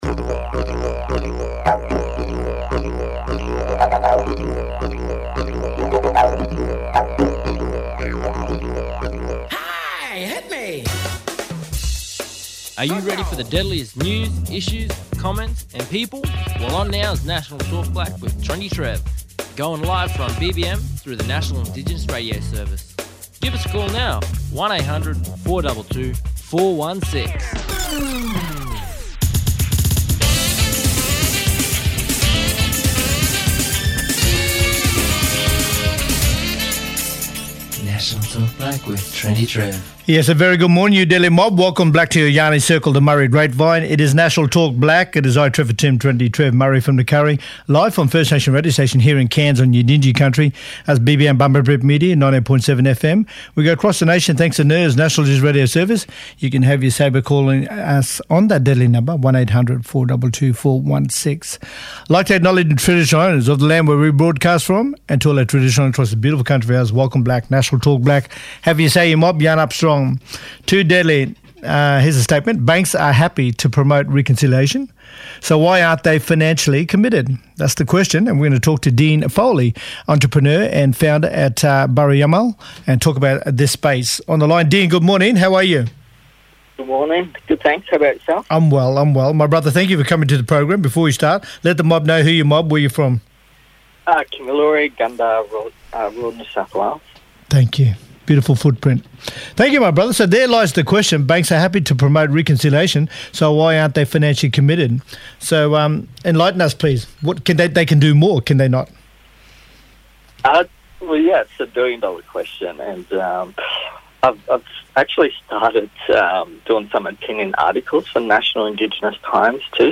Kylie Kilgour, Deputy Commissioner at IBAC Victoria’s Independent Broad-based Anti-corruption Commission , talking about IBAC audit highlights inadequacies in Victoria Police’s handling of complaints by Aboriginal people.